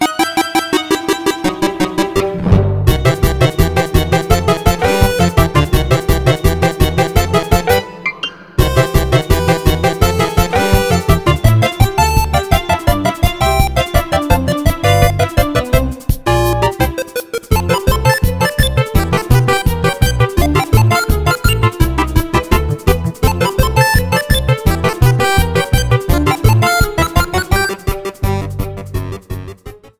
Trim and fade out